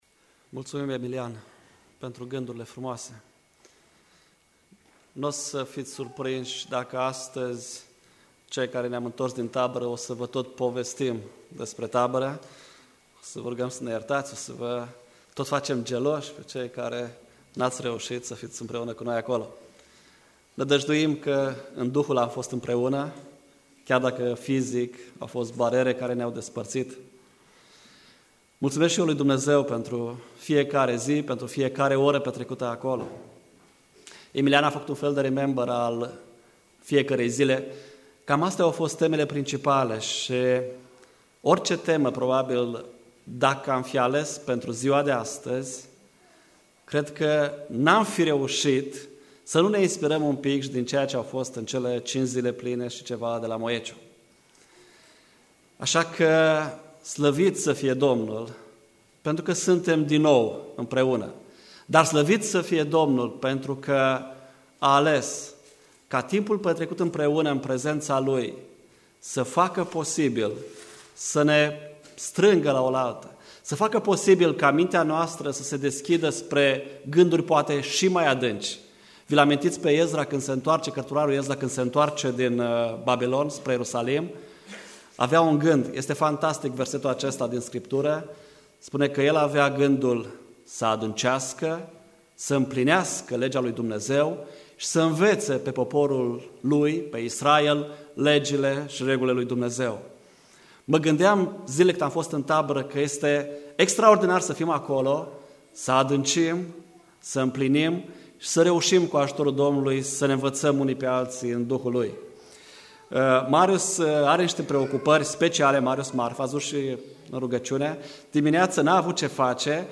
Predica